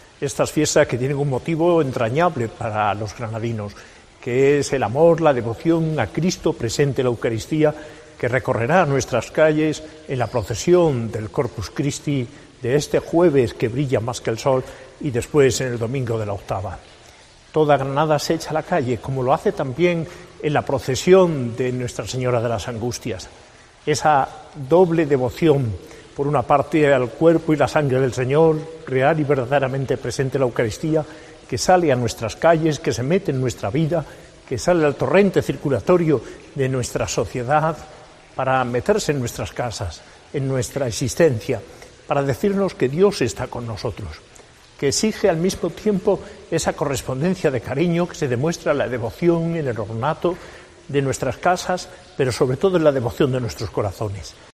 Mensaje del arzobispo de Granada, Mons. José María Gil Tamayo, con motivo de la festividad del Corpus Christi en Granada el jueves 30 de mayo y su Solemnidad el domingo
Monseñor Jose María Gil Tamayo, arzobispo de Granada